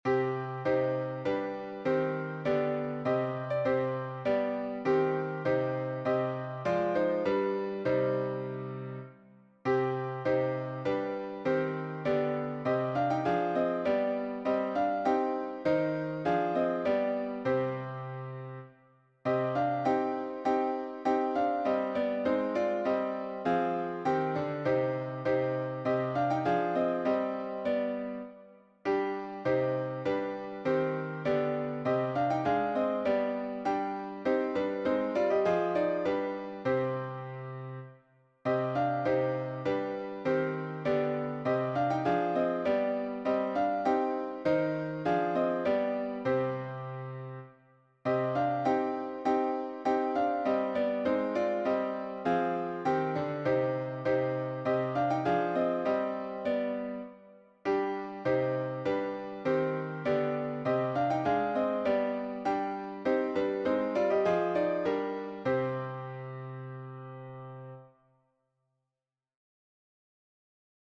Meter: 8.6.8.6
Key: c minor or modal